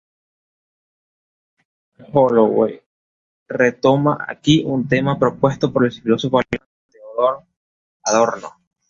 /w/